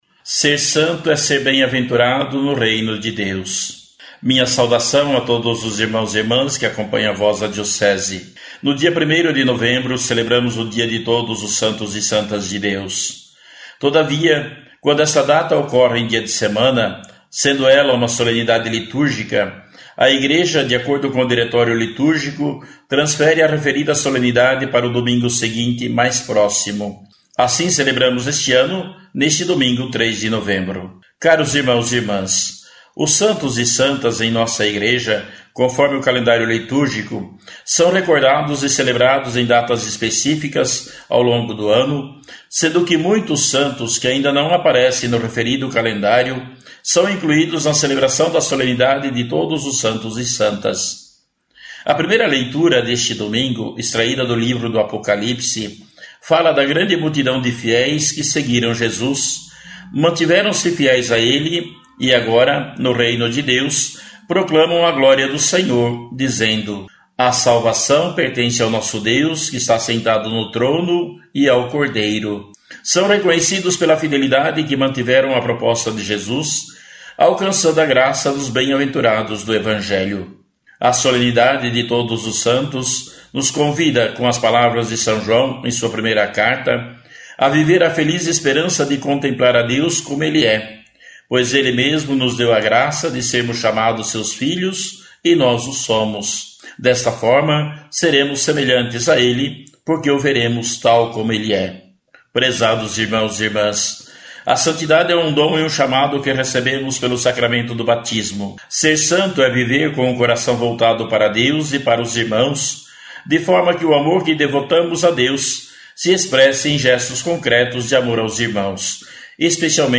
Dom Adimir Antonio Mazali – Bispo Diocesano de Erexim – RS